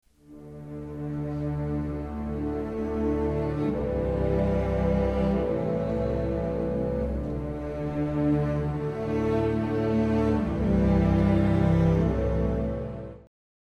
While Debussy had, by this time, almost escaped from under the long shadow cast by Wagner, it is still (easily) possible to find examples of Wagnerian leitmotif technique in Pelléas; here are three for you to memorise from the very opening of the opera: